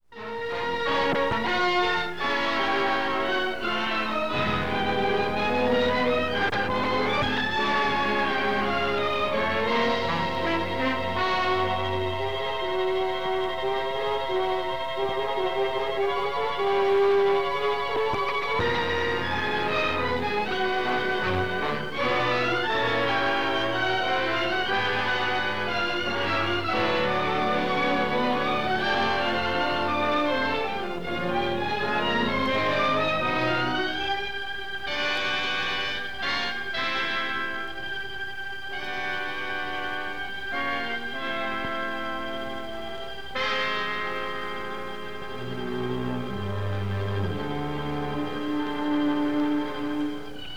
musica di repertorio